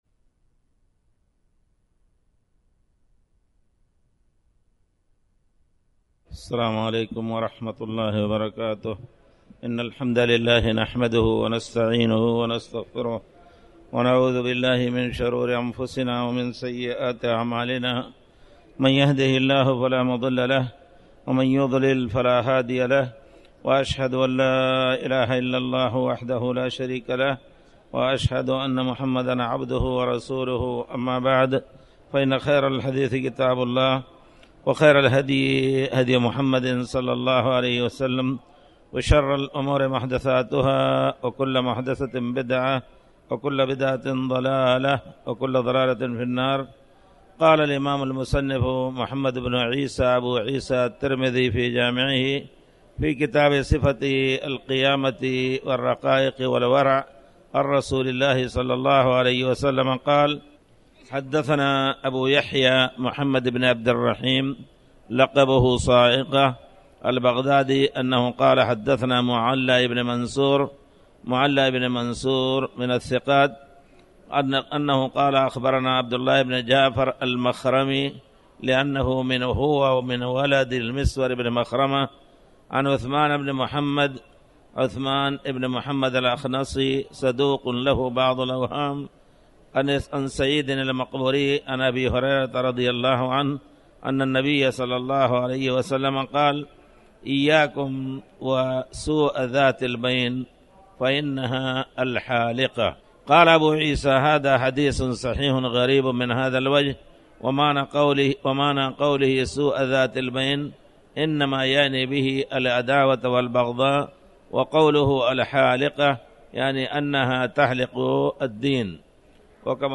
تاريخ النشر ٢٢ جمادى الآخرة ١٤٣٩ هـ المكان: المسجد الحرام الشيخ